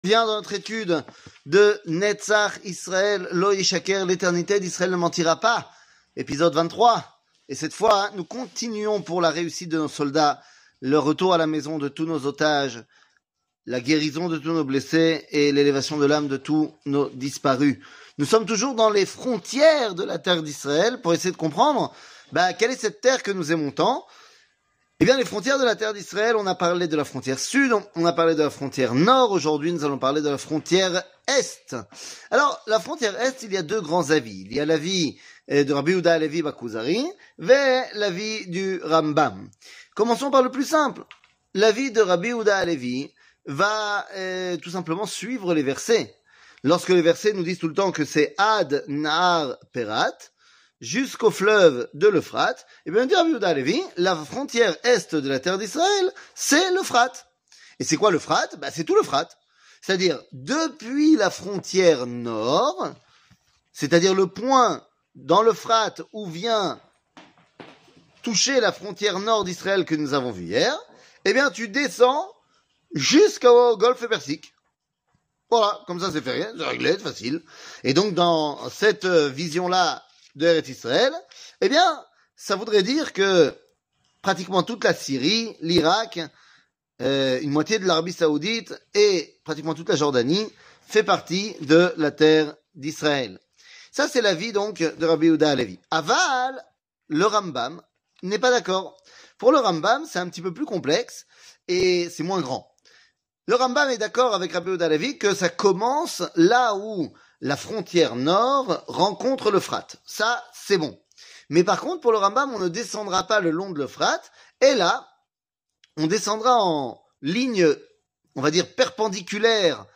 L'éternité d'Israël ne mentira pas ! 23 00:02:53 L'éternité d'Israël ne mentira pas ! 23 שיעור מ 08 נובמבר 2023 02MIN הורדה בקובץ אודיו MP3 (2.63 Mo) הורדה בקובץ וידאו MP4 (5.26 Mo) TAGS : שיעורים קצרים